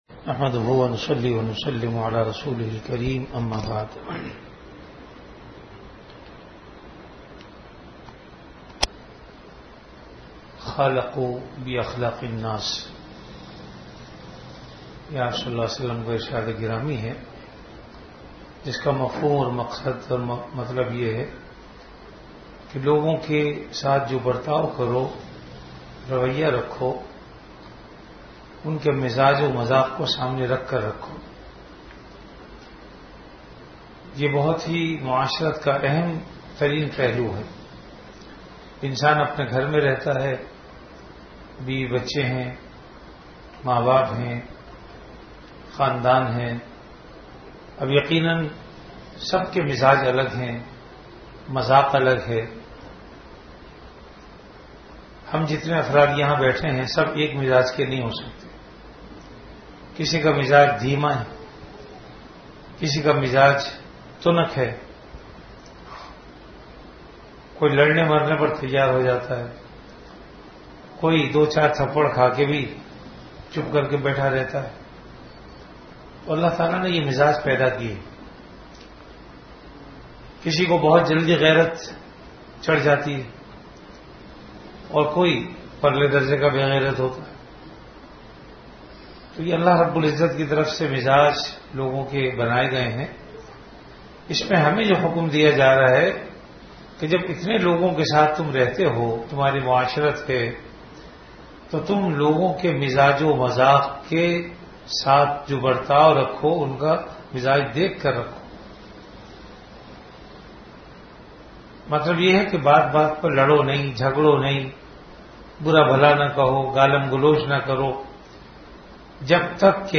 Delivered at Home.
Majlis-e-Zikr
Event / Time After Magrib Prayer